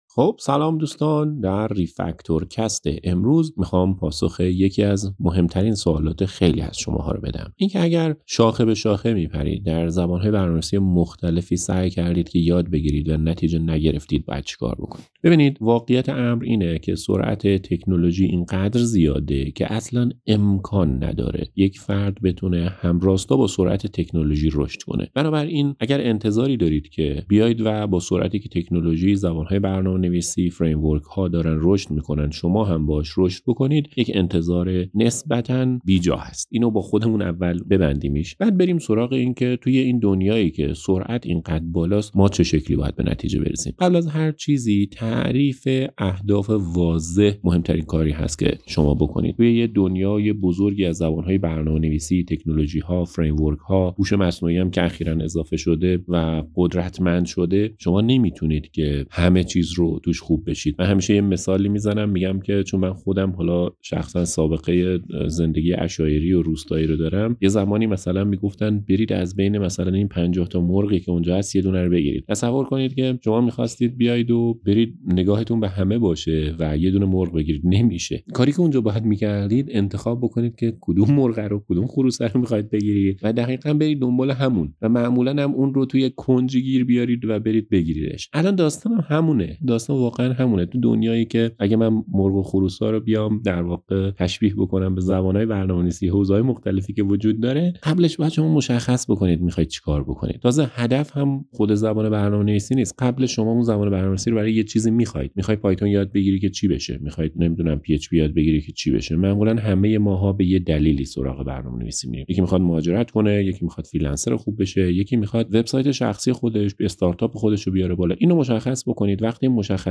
۵۰ پادکست صوتی خام که هر کدام ۱۰ دقیقه هستند نیازمند ویرایش و تدوین طبق یک سبک خاص هستند مه این ۵۰ پادکست از یک سبک پیروی می‌کنند یک نمونه از پادکست الصاق شده